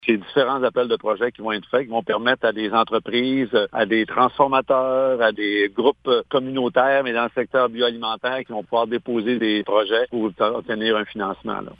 Le ministre de l’Agriculture, des Pêcheries et de l’Alimentation, André Lamontagne, donne plus précisions :